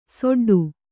♪ soḍḍu